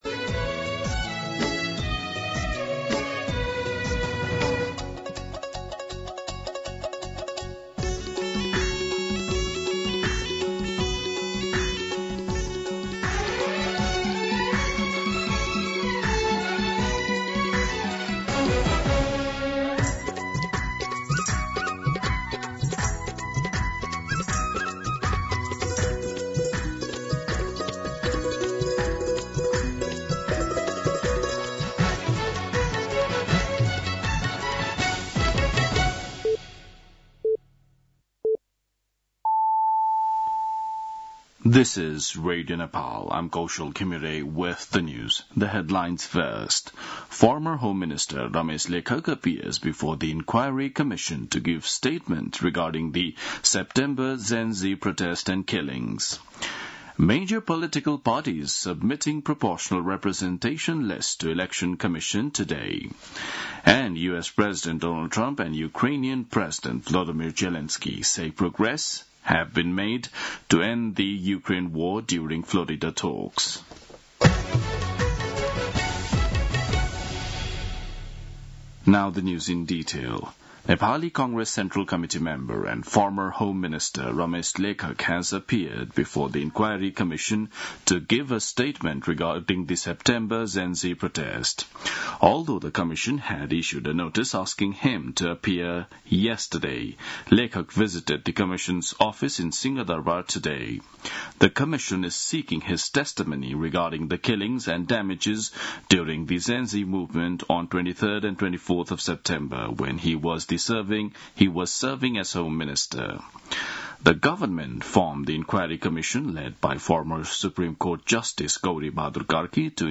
दिउँसो २ बजेको अङ्ग्रेजी समाचार : १४ पुष , २०८२